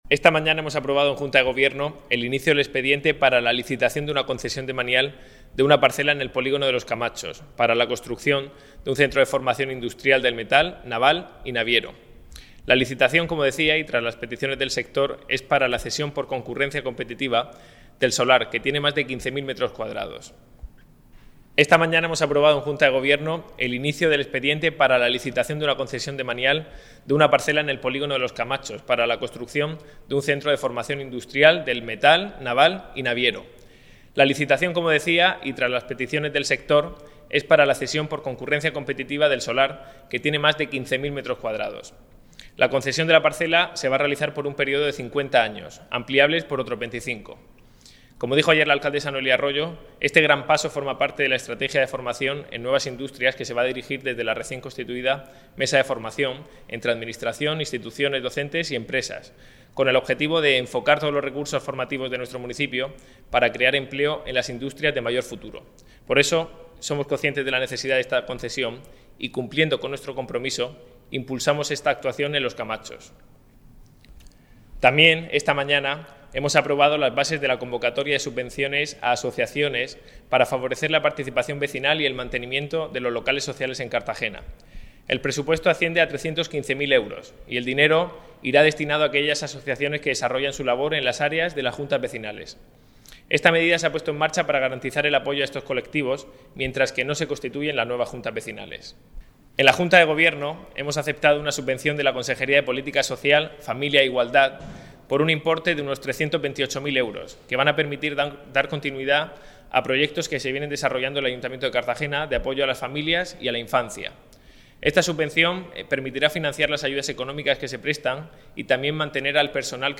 Enlace a Declaraciones de Nacho Jáudenes.